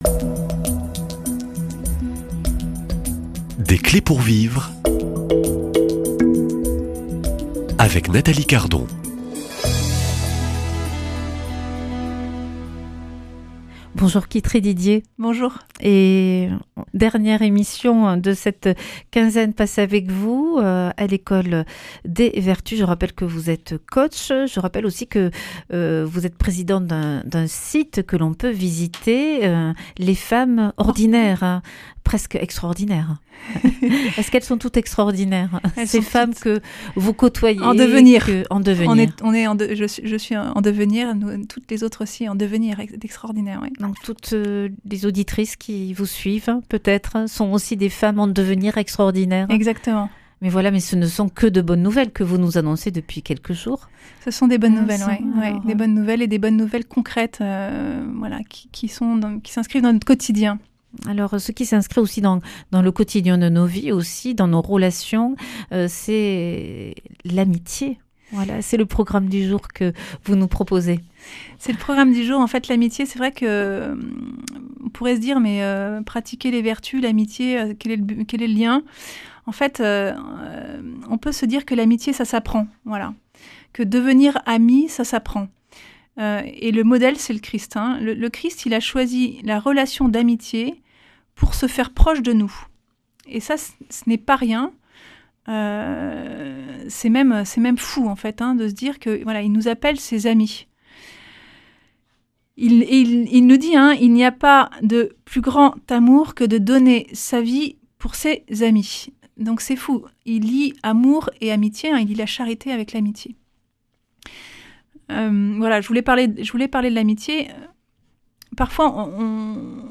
Une émission présentée par
Journaliste